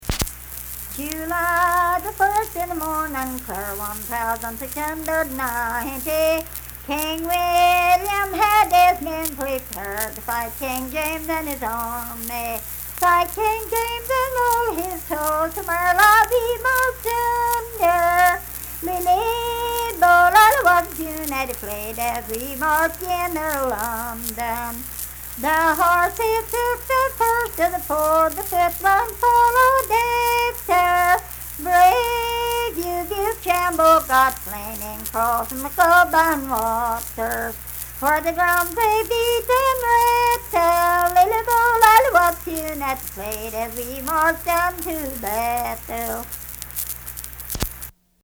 Unaccompanied vocal music
Verse-refrain 3(4).
Voice (sung)